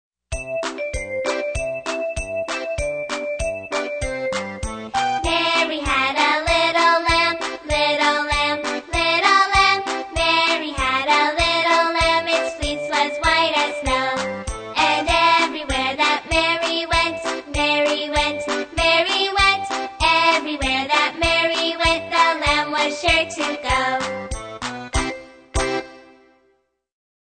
在线英语听力室英语儿歌274首 第132期:Mary Had a Little Lamb的听力文件下载,收录了274首发音地道纯正，音乐节奏活泼动人的英文儿歌，从小培养对英语的爱好，为以后萌娃学习更多的英语知识，打下坚实的基础。